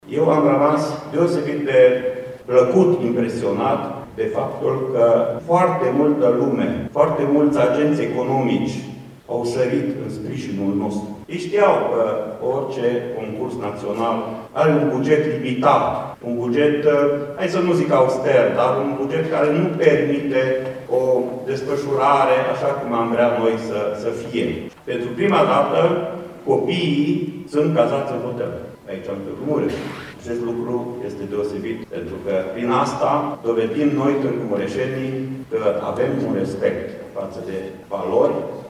Deschiderea oficială a competiţiei a avut loc aseară, în prezenţa elevilor participanţi, ai profesorilor, ai inspectorilor şcolari şi ai reprezentanţilor autorităţilor locale.
Inspectorul școlar general al județului Mureș, Ștefan Someșan, a subliniat implicarea agenţilor economici în organizarea olimpiadei.